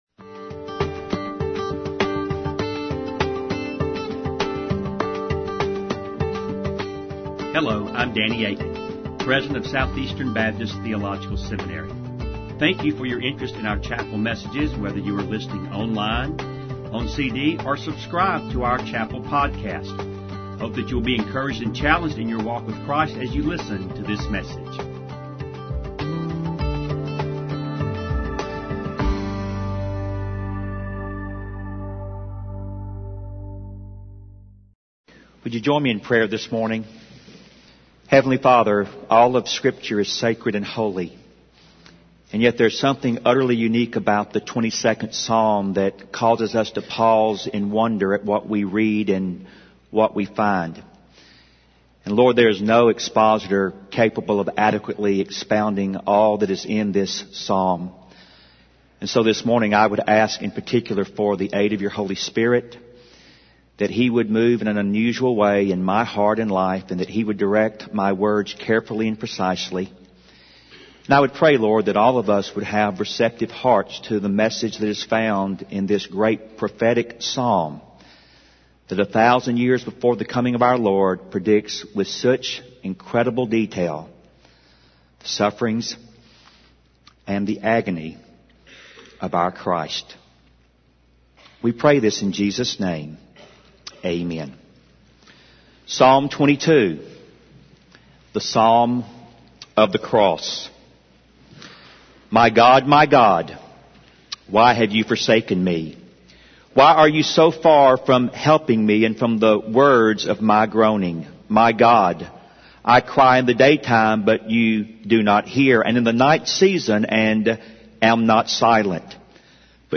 Southeastern Baptist Theological Seminary chapel message